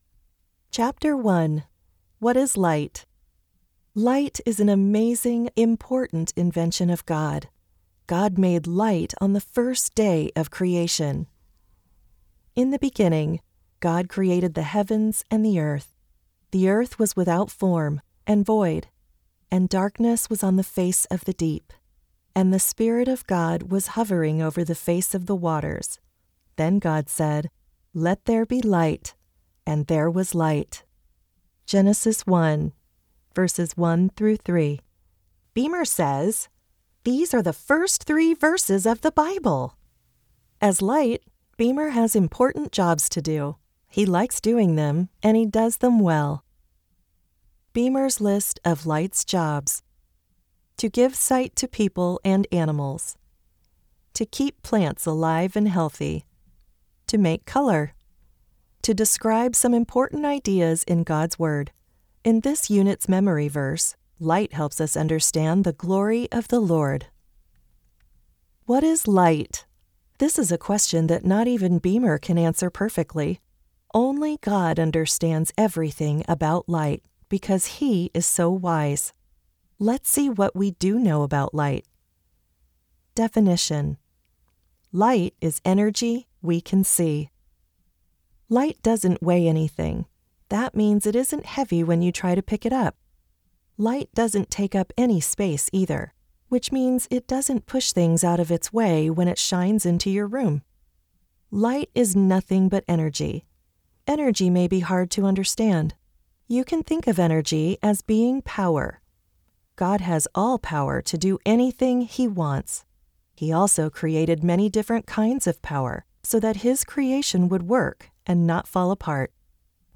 God Made Everything Textbook - Audiobook